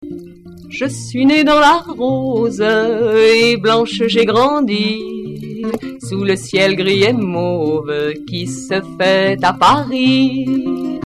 Archives d'époque à la guitare